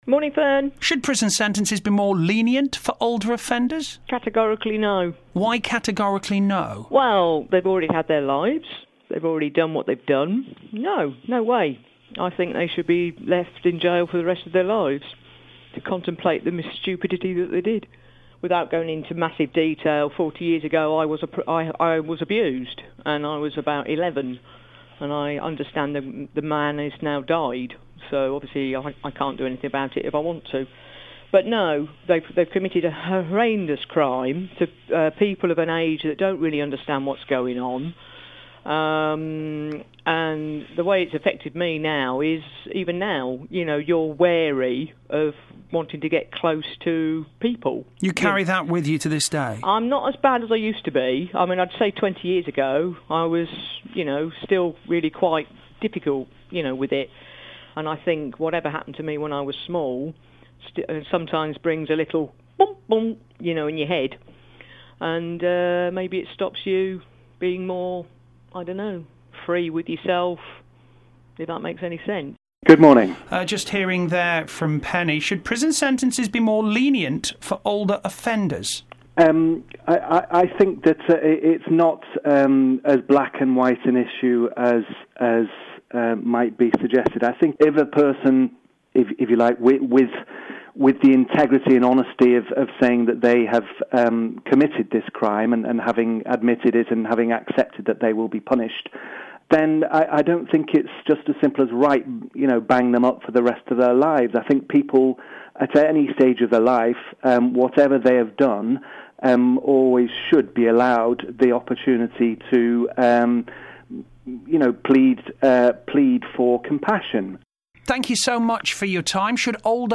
Phone in